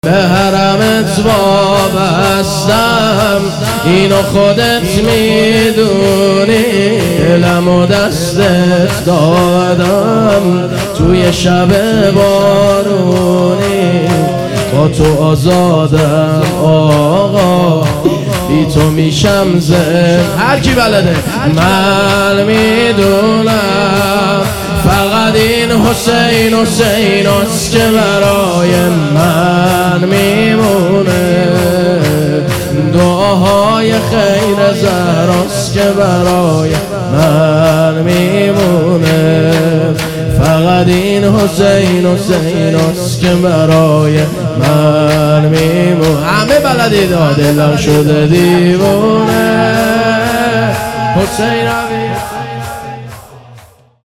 حسینیه کربلا اندرزگو | شهادت امام صادق علیه السلام 1400